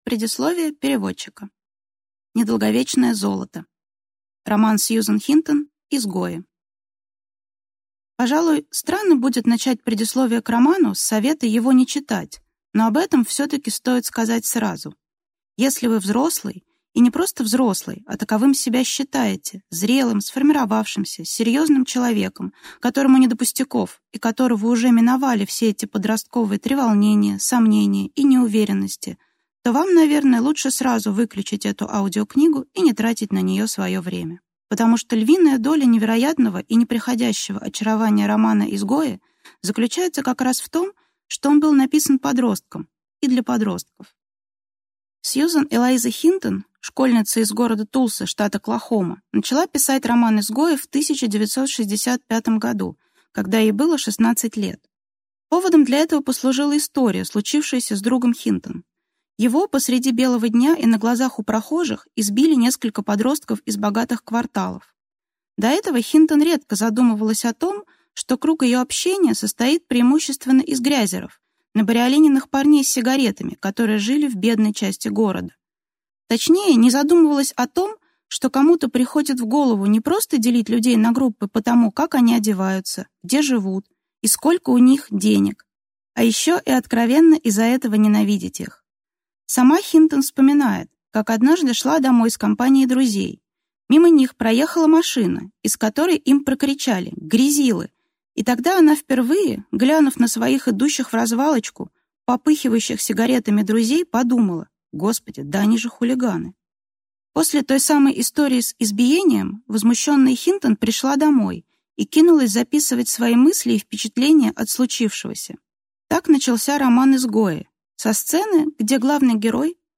Аудиокнига Изгои | Библиотека аудиокниг
Прослушать и бесплатно скачать фрагмент аудиокниги